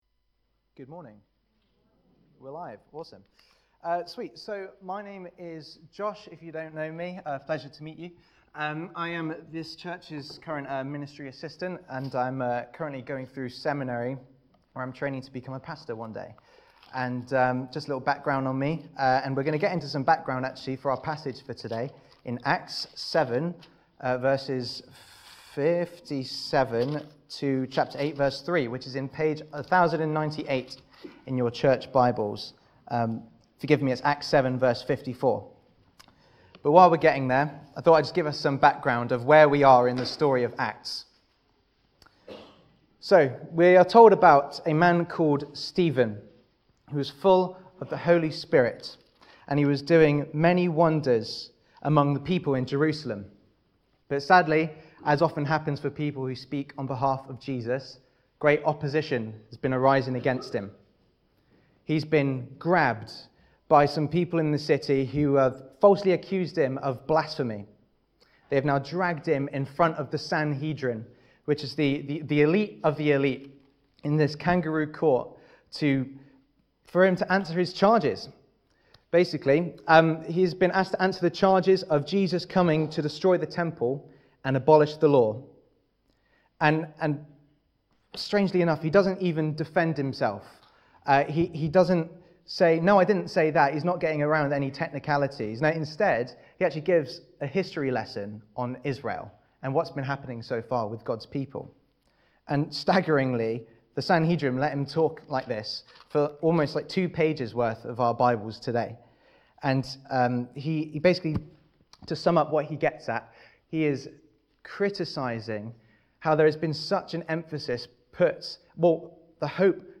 This sermon was preached on Sunday 11th February 2024 at CBC Priory Street.
Service Type: CBC @ Priory Street